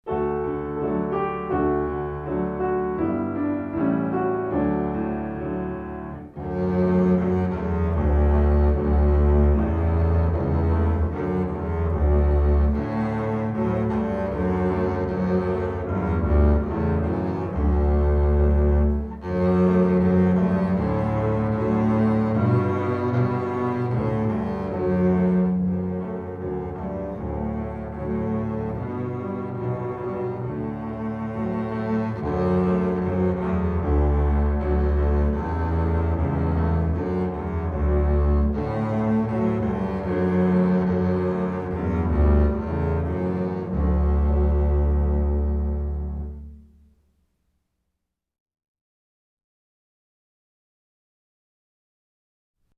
Voicing: String Bass Method w/ Audio